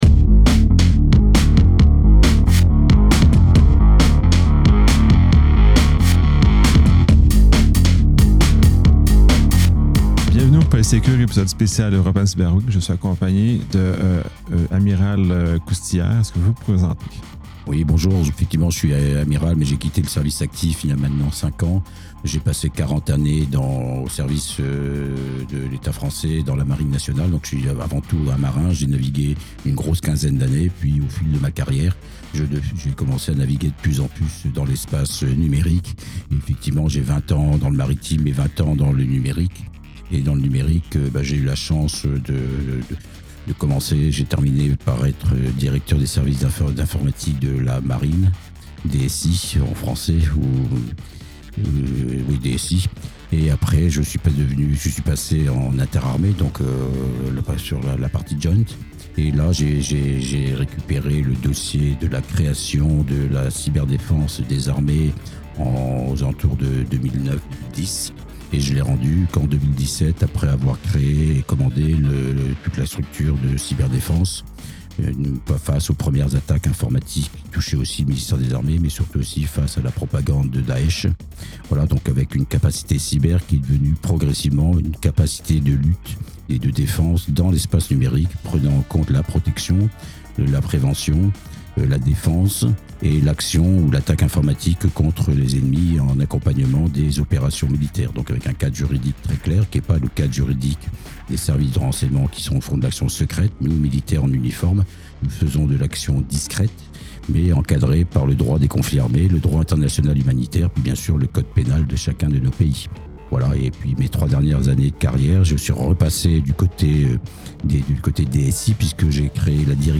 Ce podcast spécial European Cyber Week met en lumière les enjeux de la cyberdéfense, de la souveraineté numérique et de la collaboration internationale à travers l’expérience d’Arnaud Coustillière, ancien amiral de la Marine nationale française.